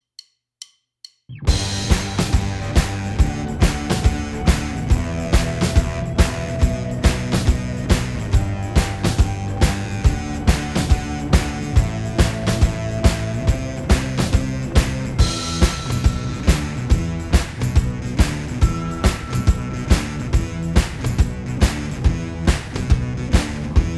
Minus All Guitars Pop (2010s) 3:22 Buy £1.50